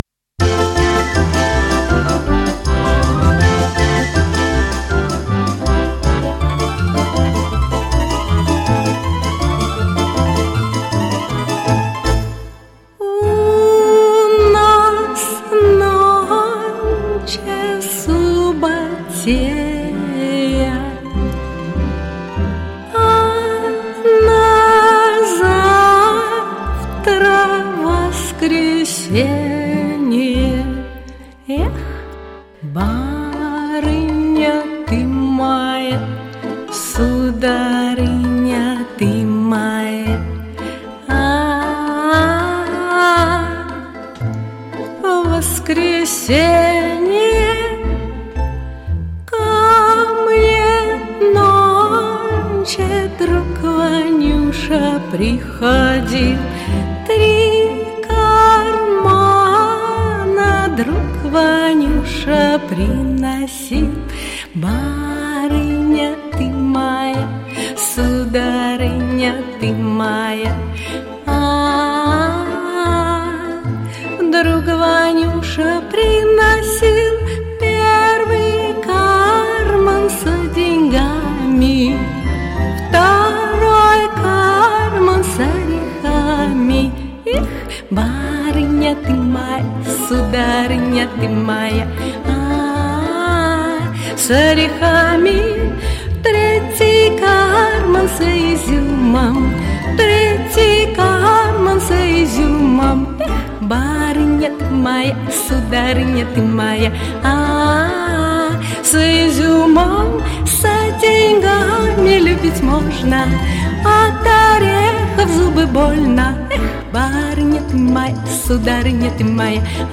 Очень красиво спели!